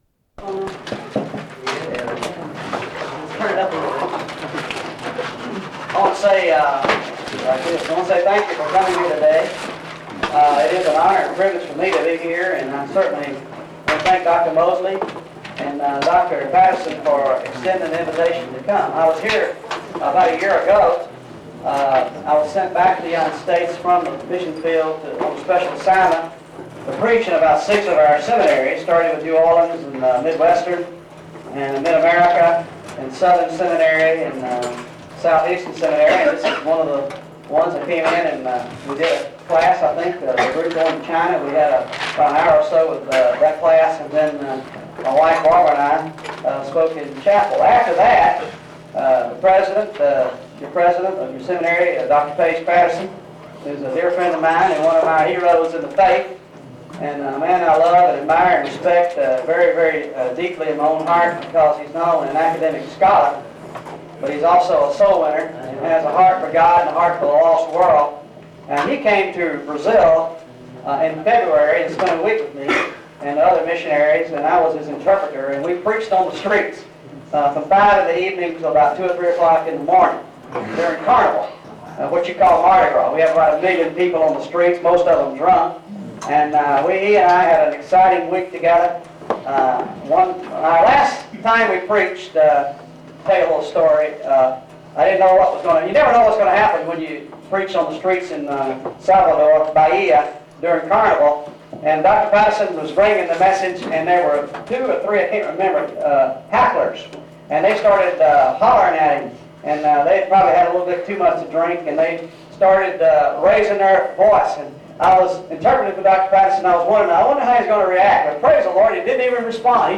Special Lecture on Harvest Missions